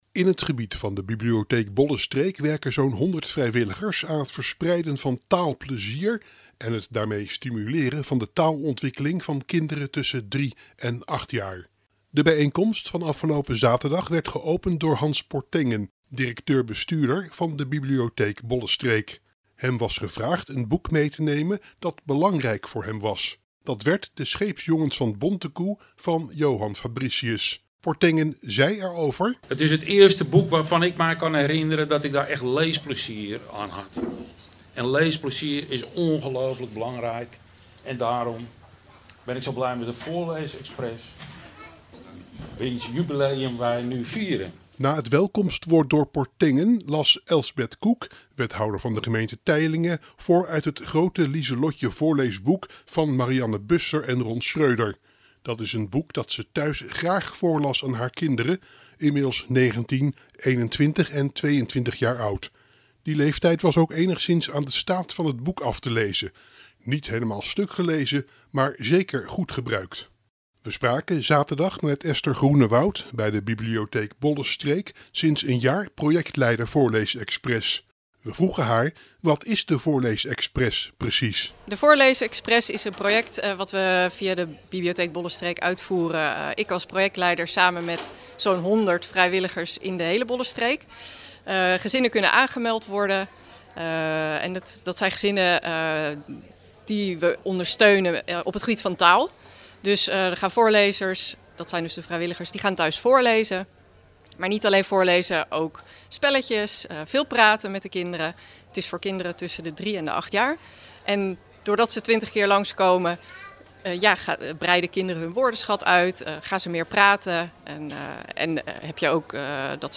Lisse – De VoorleesExpress van de Bibliotheek Bollenstreek heeft zaterdag het 10-jarig bestaan gevierd. Dat gebeurde met een feestelijke bijeenkomst in de bibliotheek van Lisse.